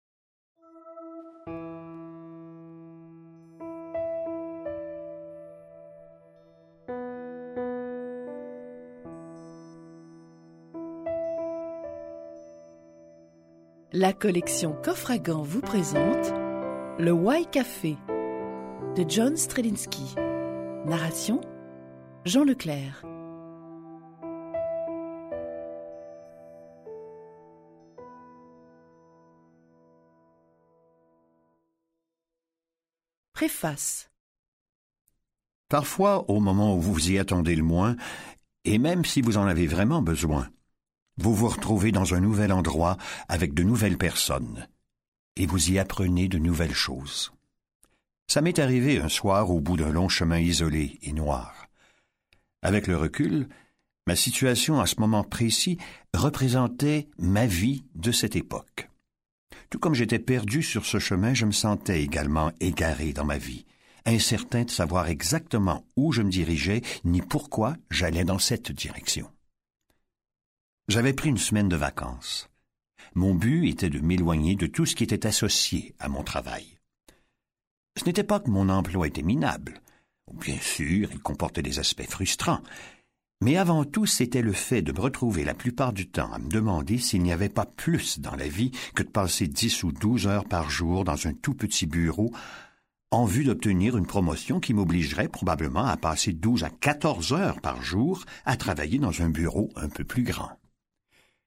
je découvre un extrait - Le why Café de John P. Strelecky